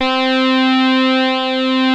OSCAR C4  5.wav